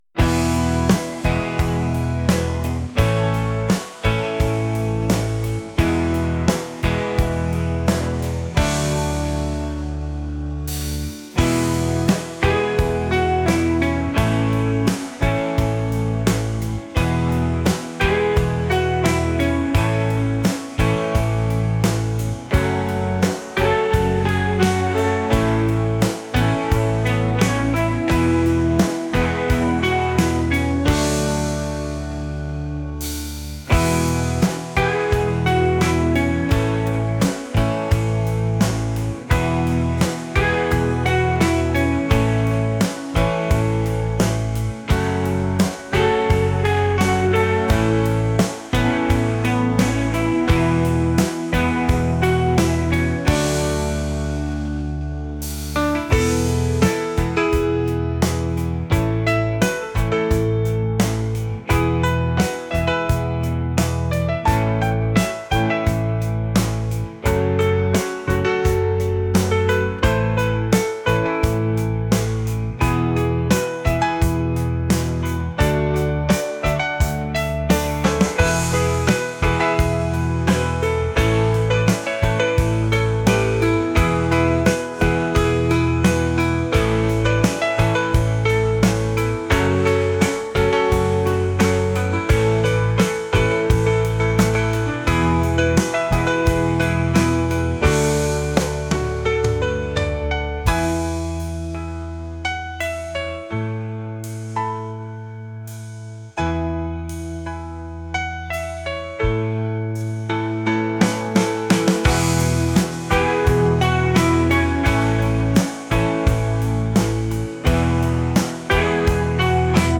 indie | rock | acoustic